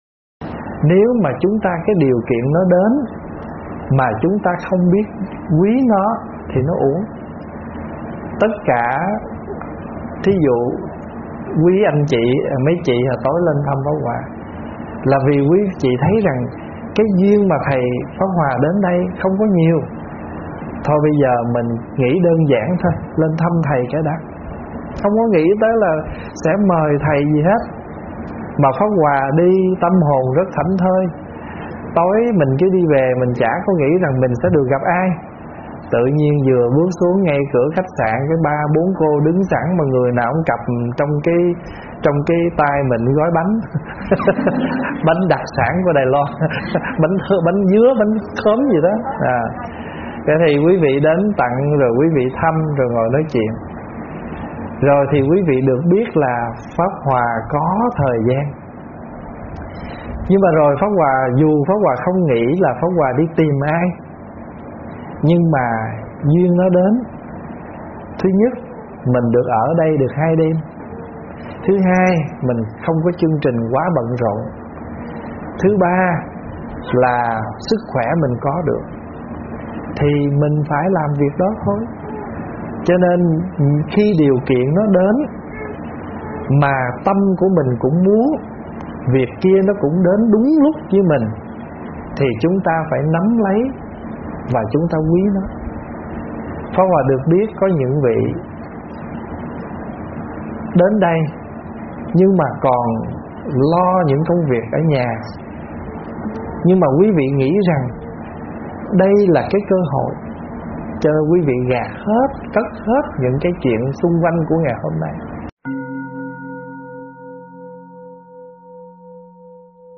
Mp3 thuyết pháp Nắm Lấy Cơ Duyên - ĐĐ.